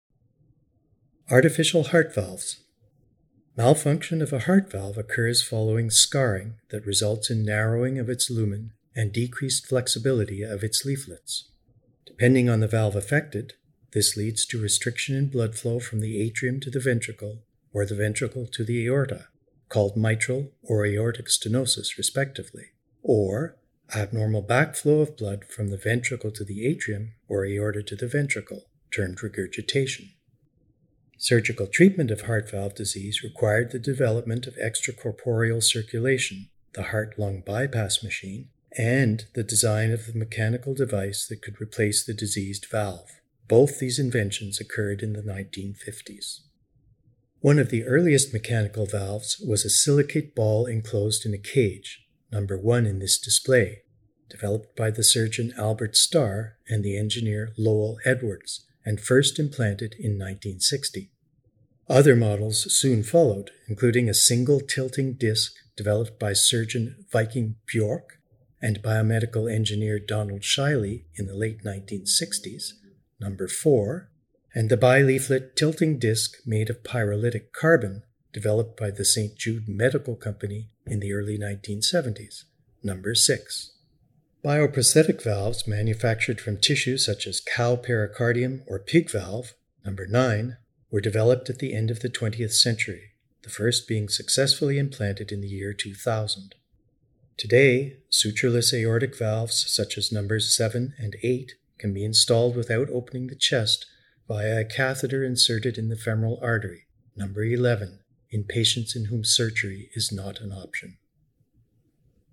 Below are audio guides for the artifacts displayed.